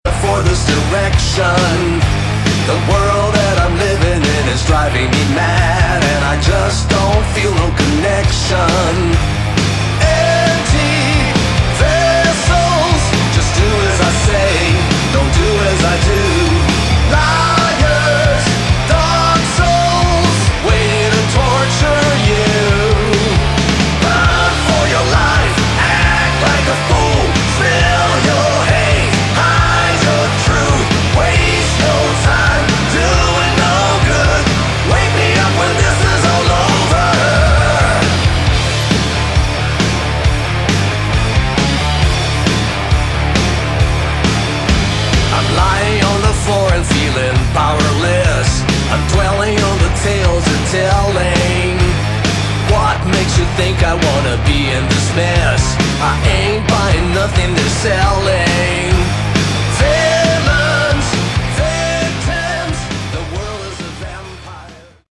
Category: Melodic Rock
bass
vocals
guitar
drums